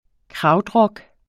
Udtale [ ˈkʁɑwd- ]